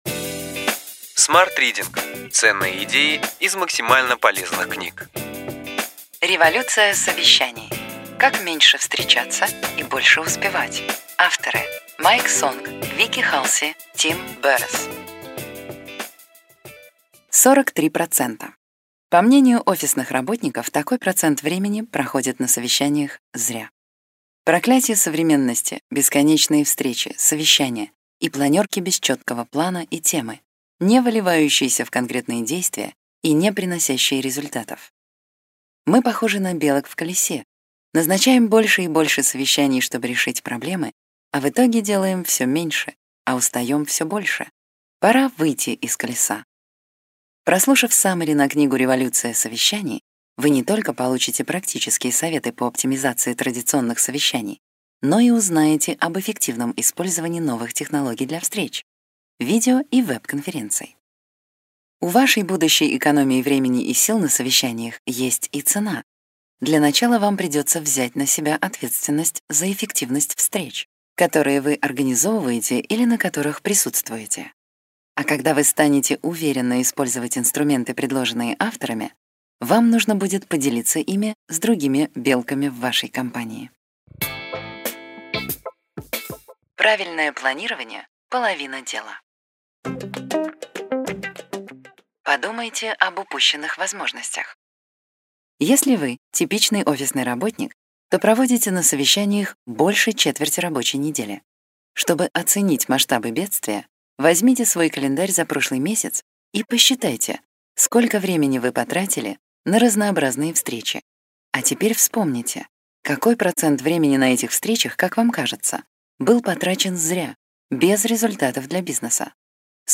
Аудиокнига Ключевые идеи книги: Революция совещаний. Как меньше «встречаться» и больше успевать.